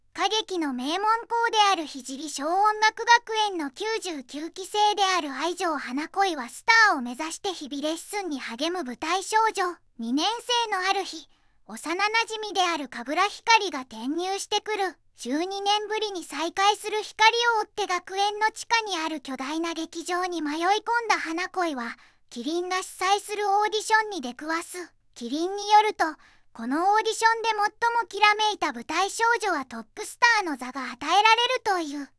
VOICEVOX: ずんだもんを利用しています